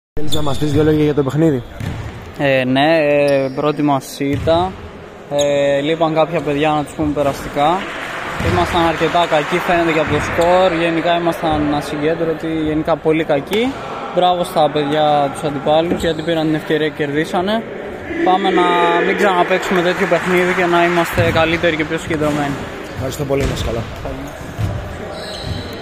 GAME INTERVIEWS: ΠΑΙΚΤΗΣ (VODAFONE) Vodafone ΠΑΙΚΤΗΣ (E-TRAVEL) Etravel GAME PHOTOS: